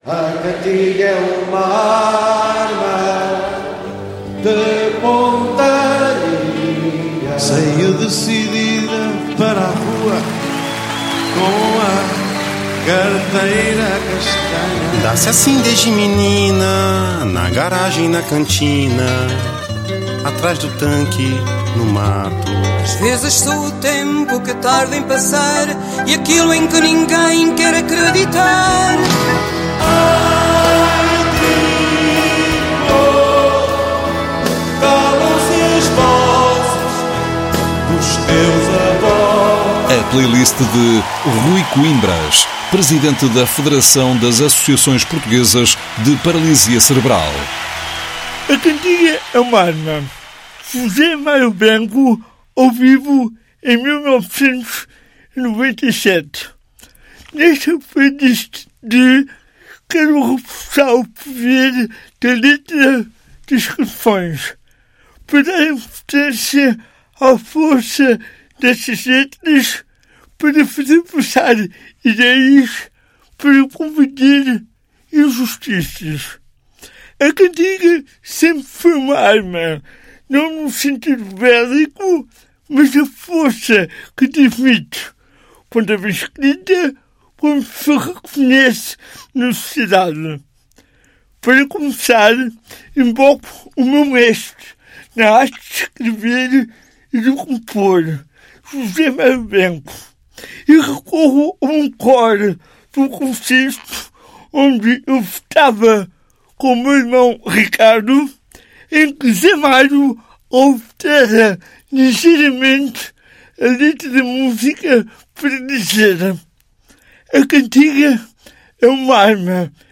A TSF vai assinalar o Dia Nacional da Paralisia Cerebral com música e sem estigmas – com a transmissão de uma emissão especial do programa “A...